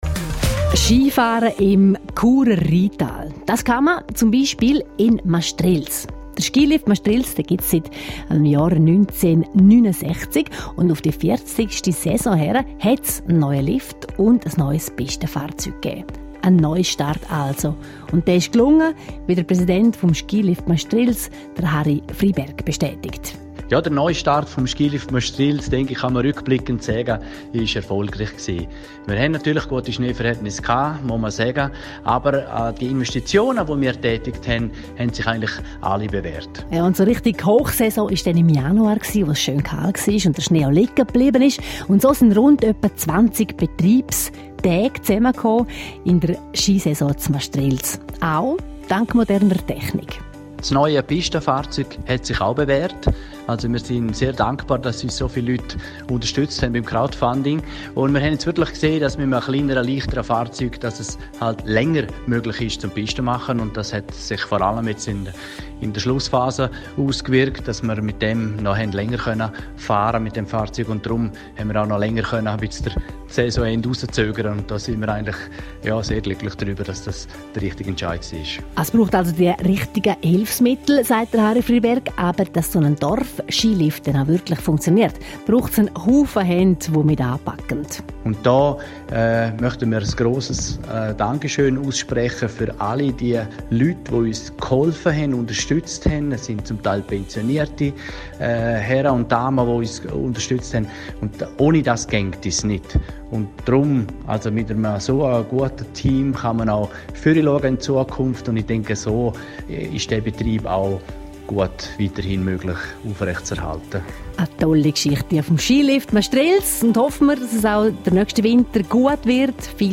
Radio Südostschweiz berichtet über Saisonabschluss: